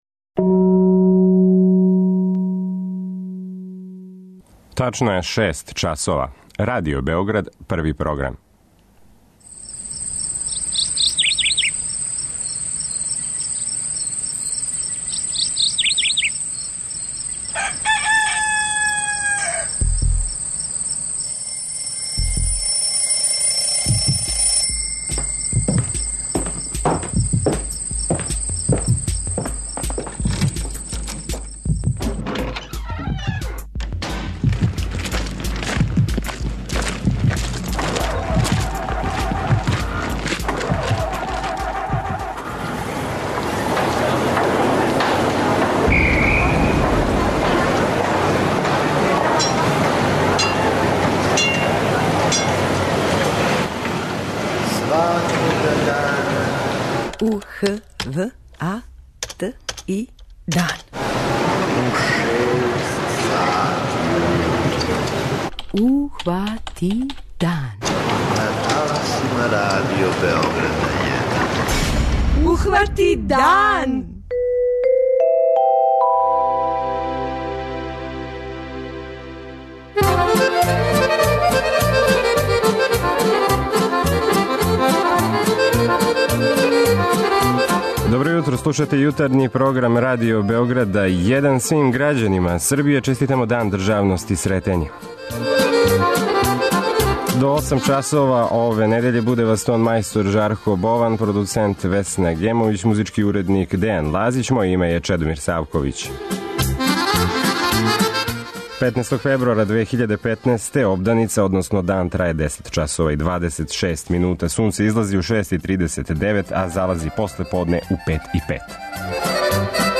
преузми : 57.28 MB Ухвати дан Autor: Група аутора Јутарњи програм Радио Београда 1!